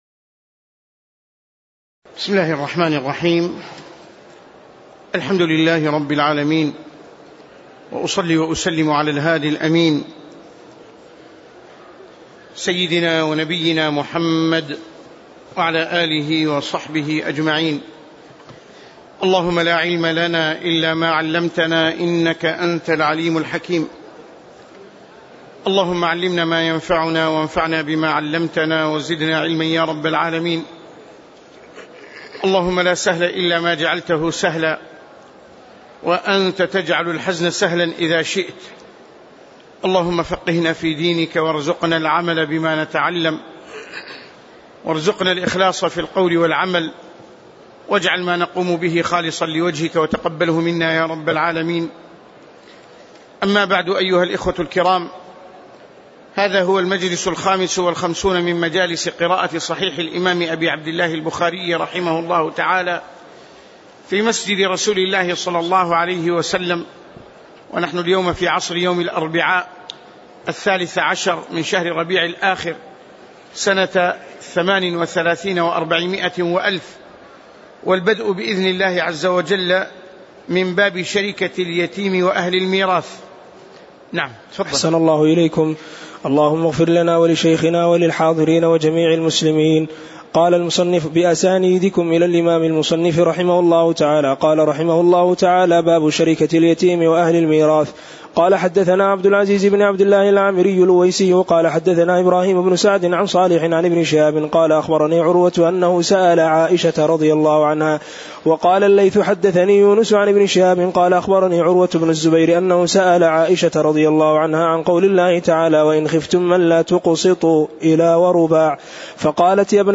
تاريخ النشر ١٣ ربيع الثاني ١٤٣٨ هـ المكان: المسجد النبوي الشيخ